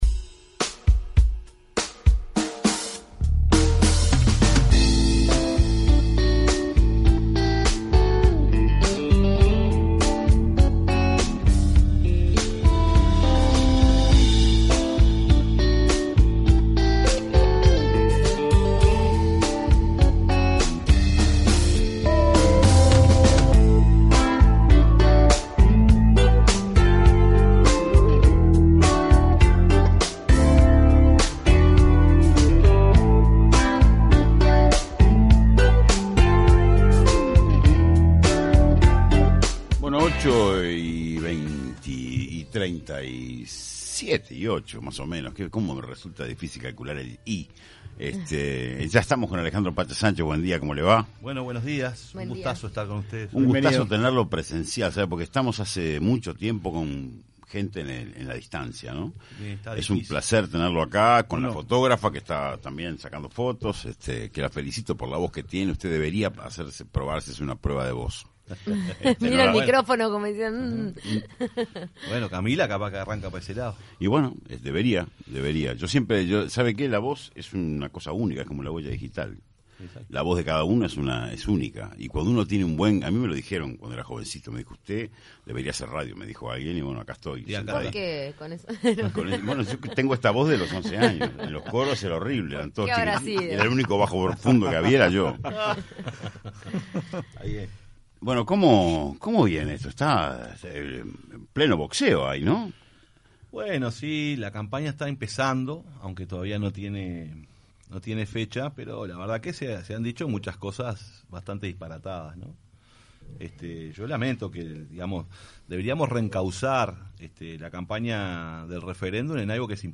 En entrevista en Punto de Encuentro el senador del Frente Amplio Alejandro Sánchez criticó la política en materia de combustibles que está llevando adelante la administración de Lacalle Pou.